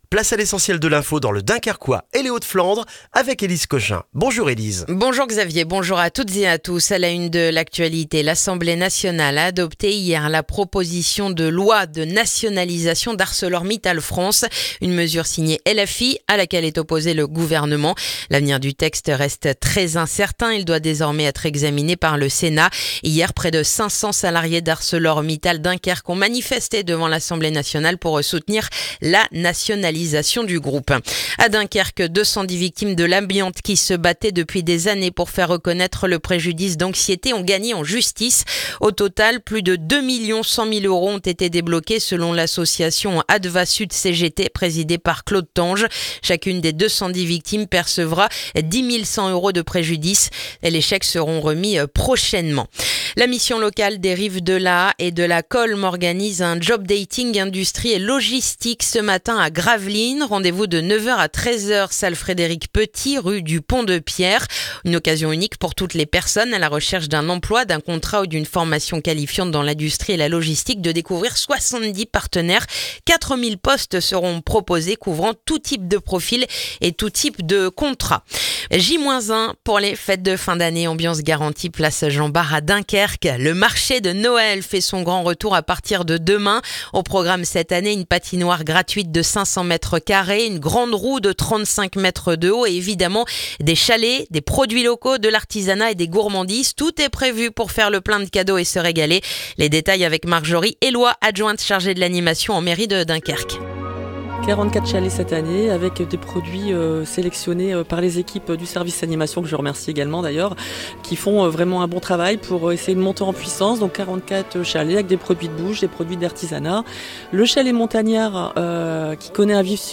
Le journal du vendredi 28 novembre dans le dunkerquois